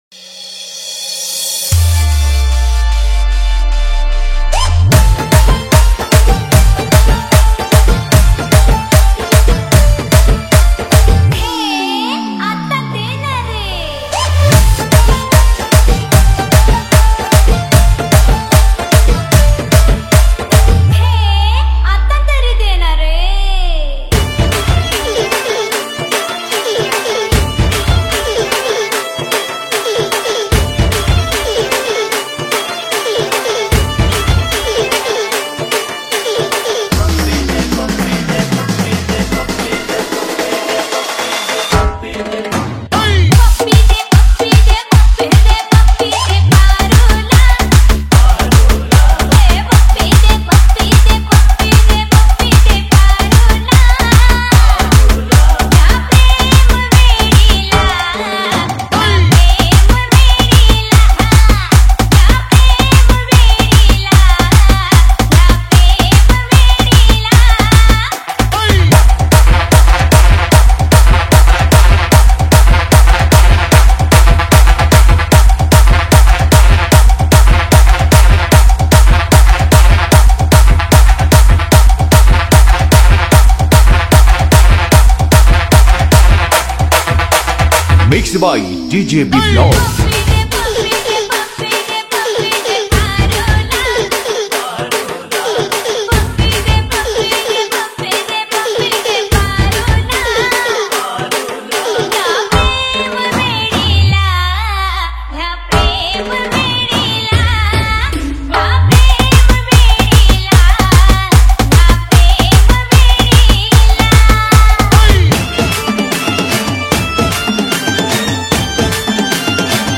Category : Bhojpuri Remix Song